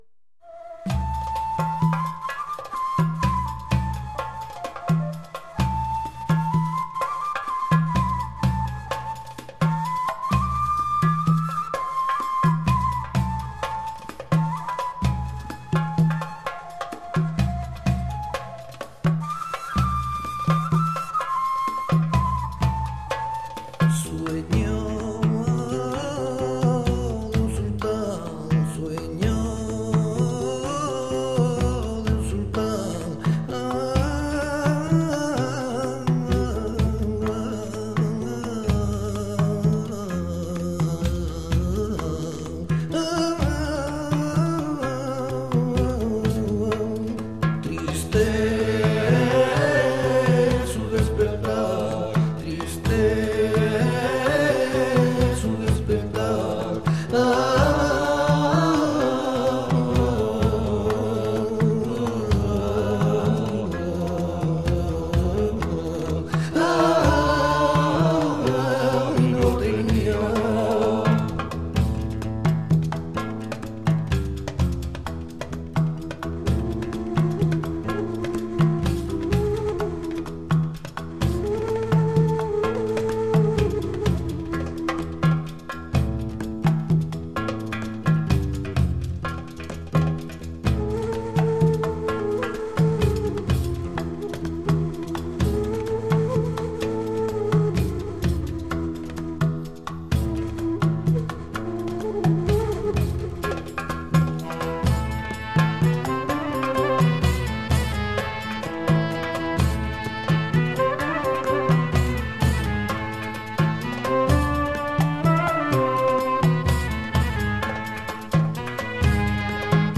une autre version de la chanson